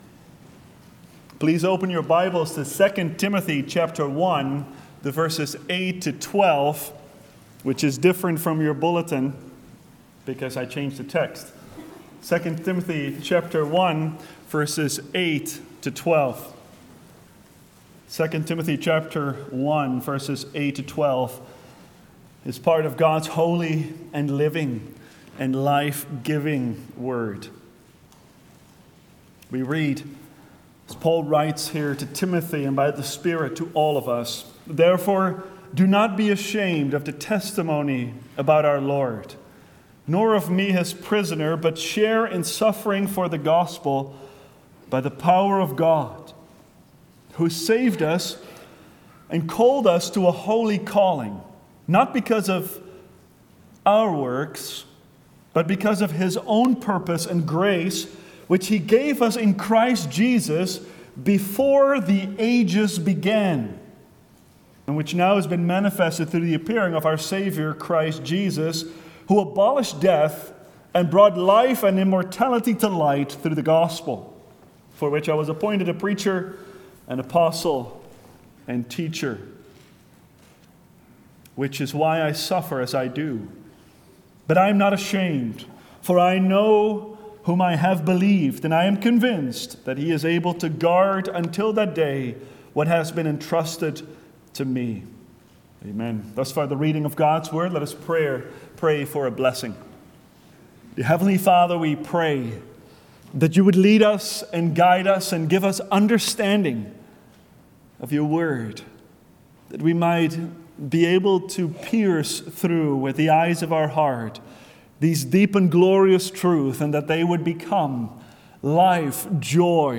Resurrection Series Passion and Easter Sermons Book 2 Timothy Watch Listen Save In 2 Timothy 1:8-12, the apostle explains that God has given us a holy calling now made visible in Christ who abolished death and brought life and immortality to light through the gospel.